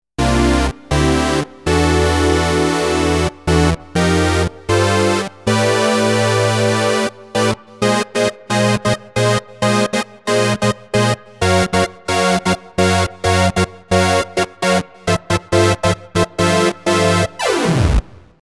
Rock Poly
定番のシンセブラス音色ですが、
音の伸び、歯切れの良さ、粒立ちの良さと